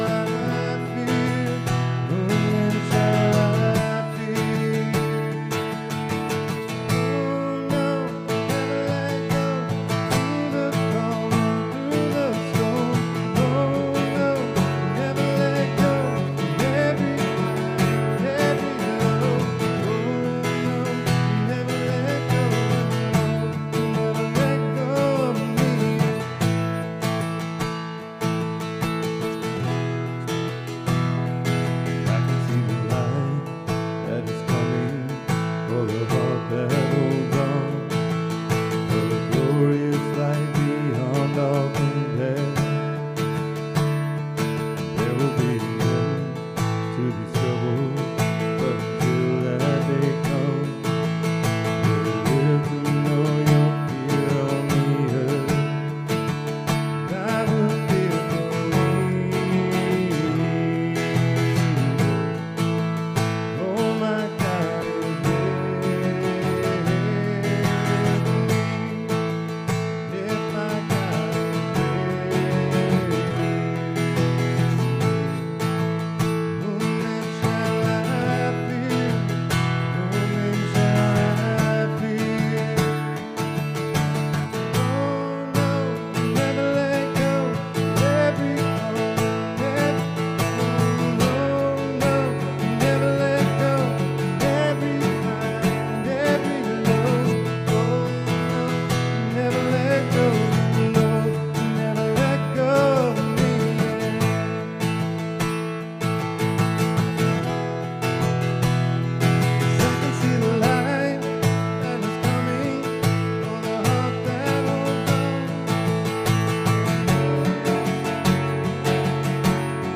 SERMON DESCRIPTION Today, we contemplate a Resilient Missionary, a theme that both challenges and inspires us on our faith journey, which leads us to important question.